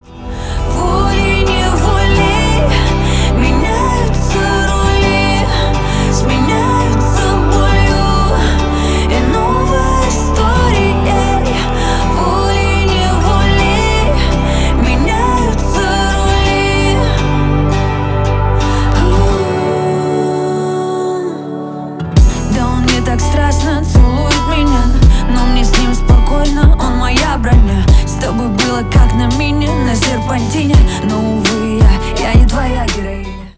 Поп Музыка # Рэп и Хип Хоп
спокойные